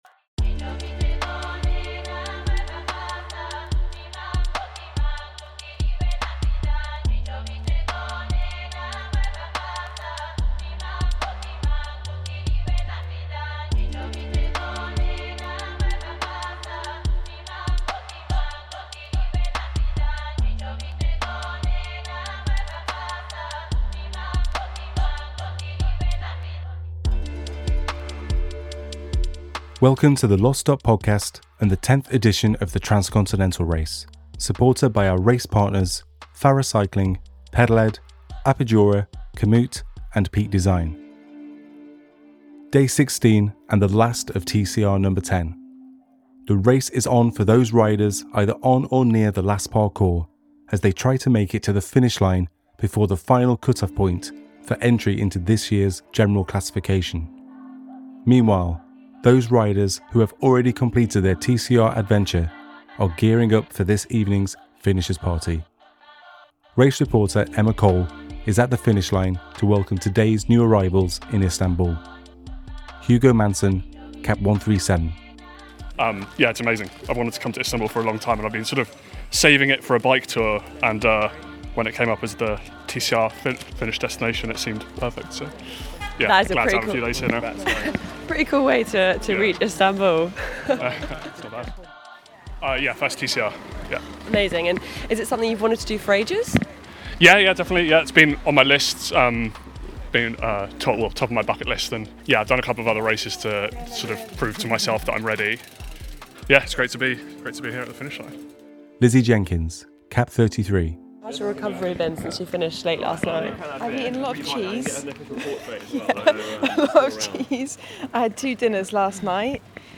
TCRNo10 | Finish Aug 07, 2024, 01:36 PM Headliner Embed Embed code See more options Share Facebook X Subscribe TCRNo10 // Finish It’s the day of the Finisher’s Party and the Race is on for riders still making their way to Istanbul. The finish line is abuzz with anticipation as the Media Team and spectators wait to see who will be the last to remain in the General Classification. As the tenth edition of the Transcontinental Race draws to a close everyone reflects on the past decade of racing. Catch up on the voices and soundscapes of TCRNo10 with our daily podcast episodes available on Spotify and Apple Podcasts.